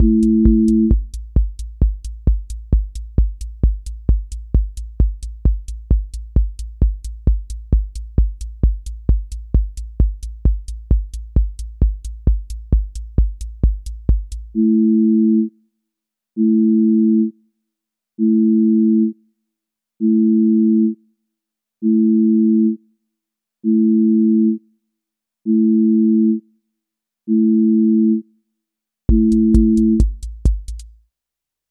trance euphoria
trance ascent with breakdown and drop
• voice_kick_808
• voice_hat_rimshot
• voice_sub_pulse
• fx_space_haze_light
• tone_brittle_edge